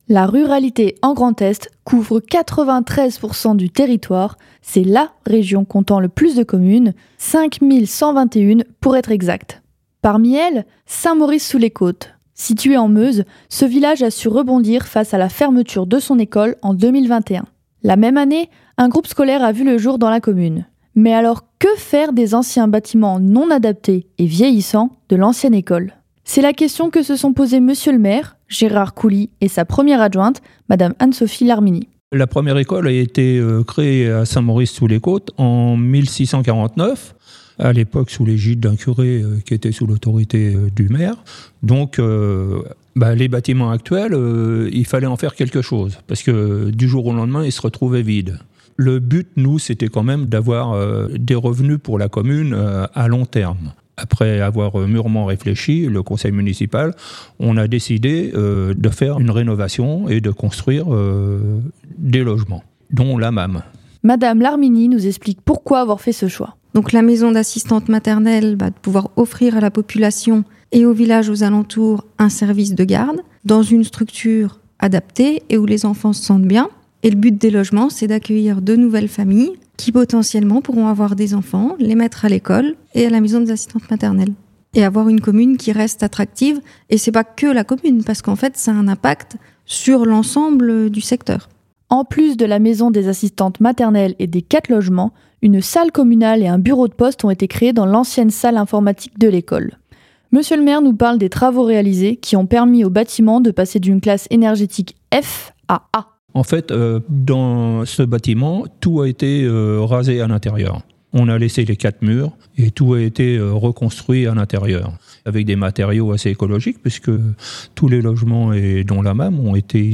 Reportage 2